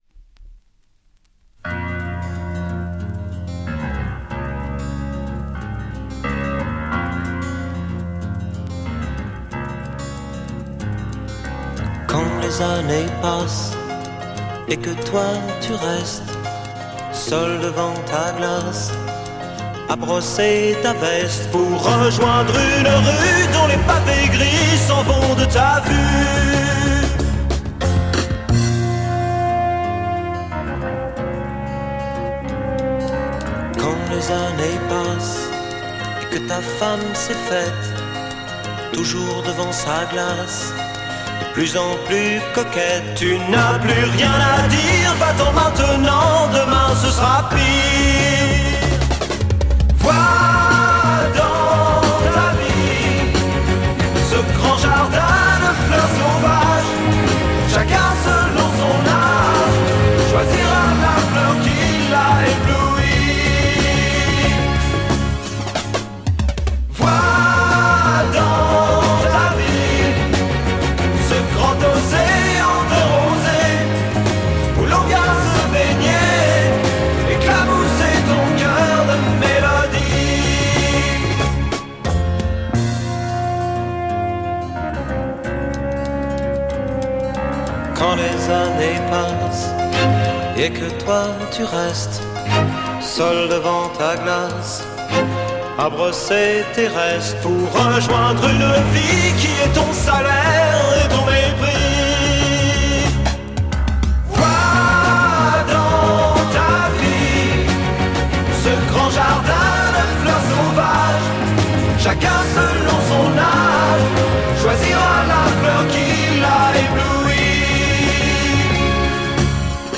French acid pop-sike Psych-glam 7"